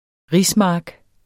Udtale [ ˈʁis- ] Betydninger mark hvor der dyrkes ris